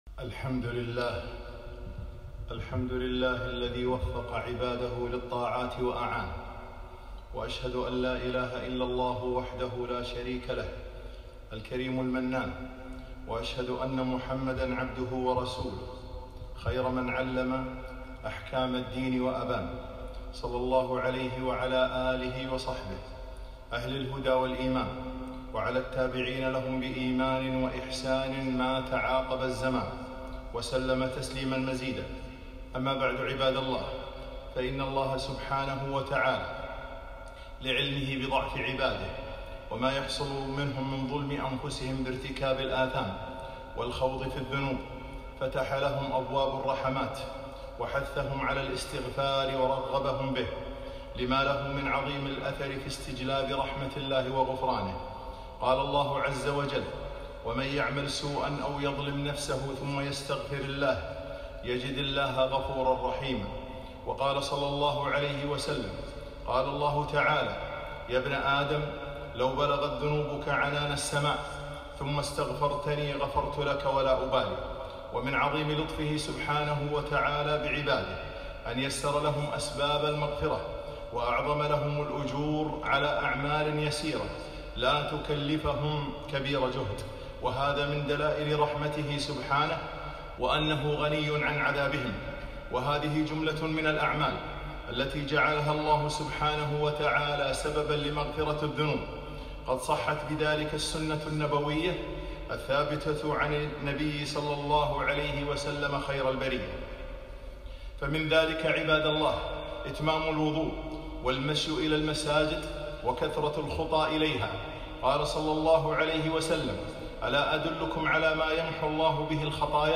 خطبة - اسباب المغفرة -13 ذي الحجة 1442